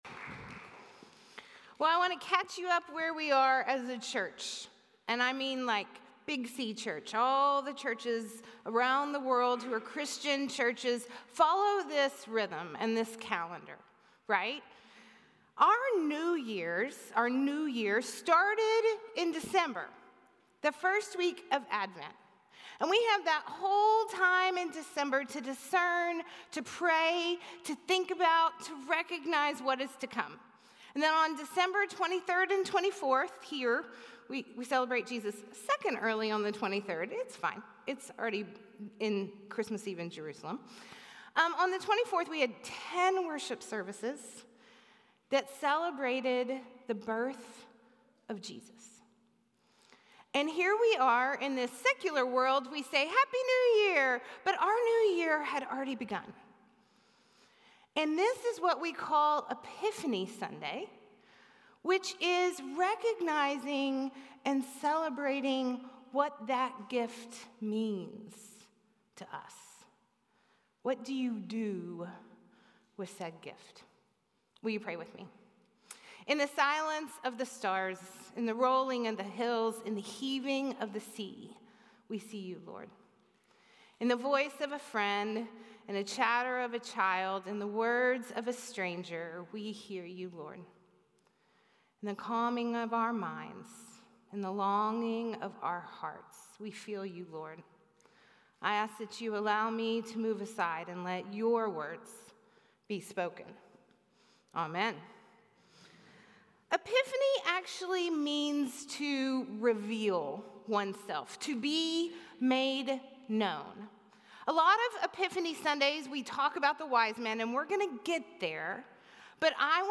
A message from the series "Advent."